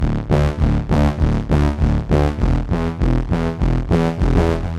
Dance music bass loops 2
Dance music bass loop - 100bpm 52